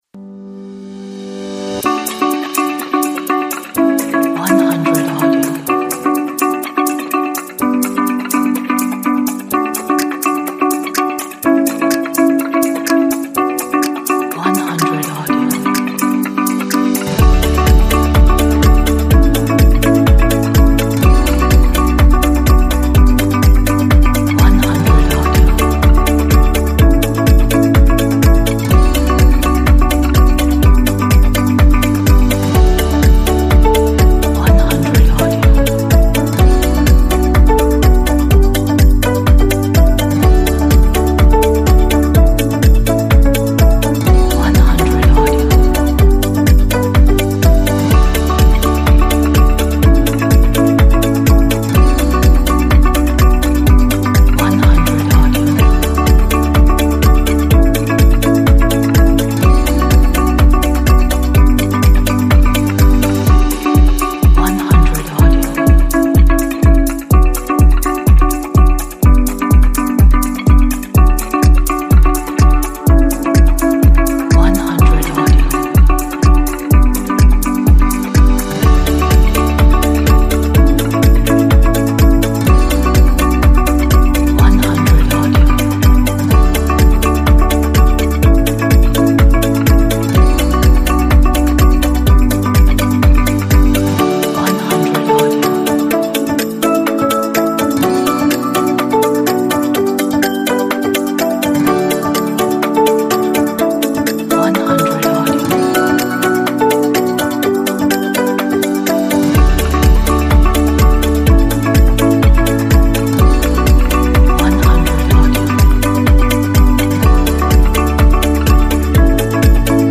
inspiring, modern background corporate track.